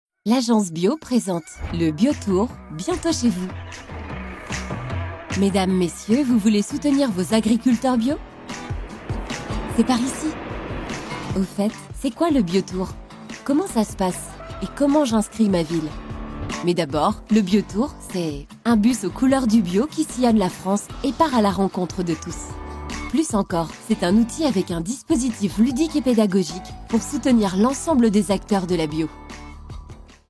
🎙 Voix-off française – Douce, sincère et naturelle
Institutionnel : Bio tour
Young Adult
Ma voix est jeune, douce, sincère, avec un léger grain qui apporte chaleur et authenticité à chaque projet.